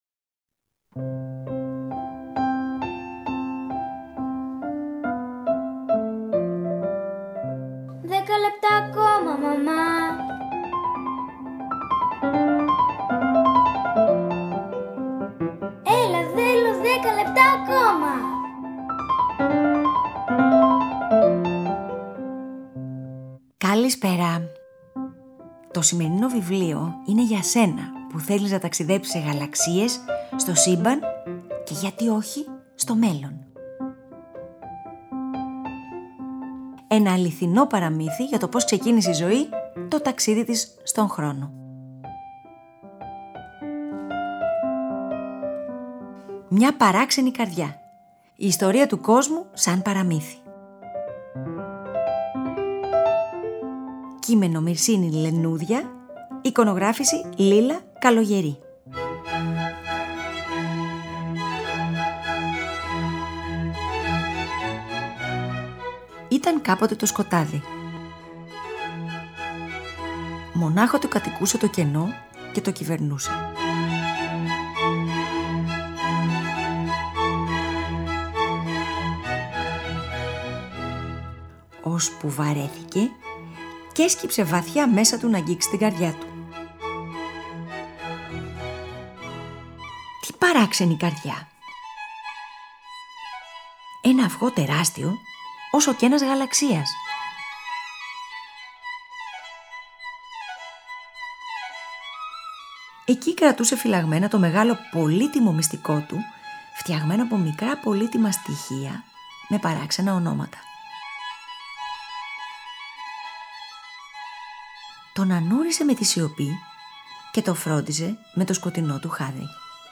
Μια παραμυθένια αφήγηση για την ιστορία του κόσμου μας. Ένα ταξίδι στο σύμπαν που απαντά σε βασικά ερωτήματα και φέρνει σε επαφή τα παιδιά με εντυπωσιακά στοιχεία όπως οι γαλαξίες, με επιστημονικές πληροφορίες όπως το Πρωτοκύτταρο, μέσα από μια φαντασιακή όσο και ρεαλιστική.